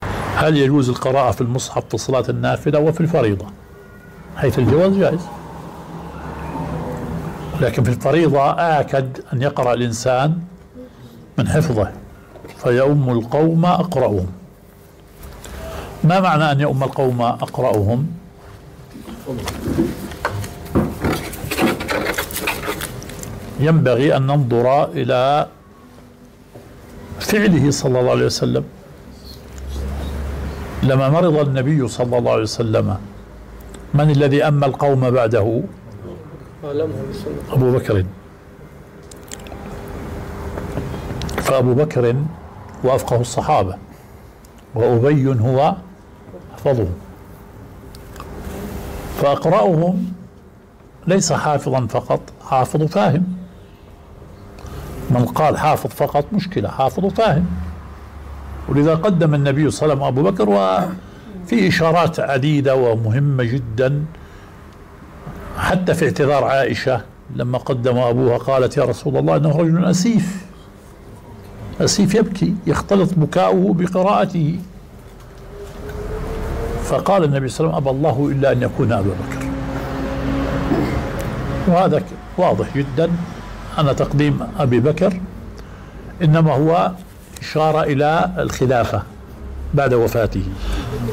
مجلس سؤال وجواب